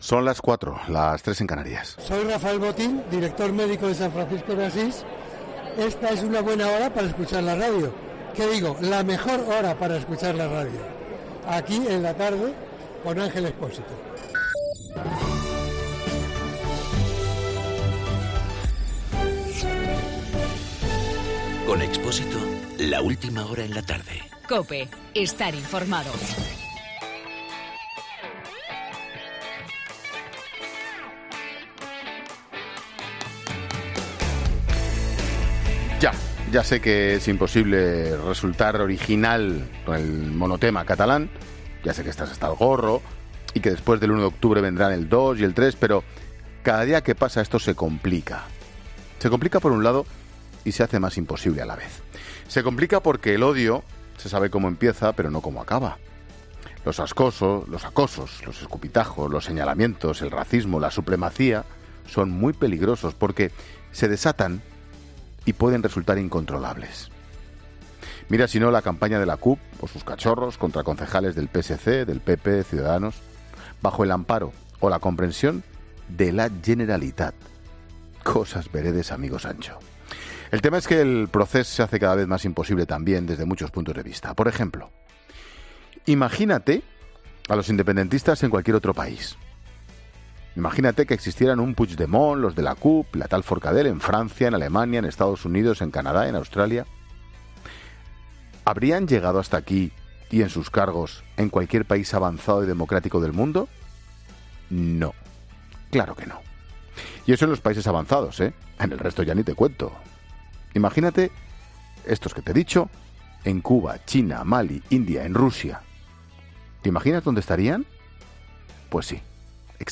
Monólogo de Ángel Expósito sobre el problema catalán.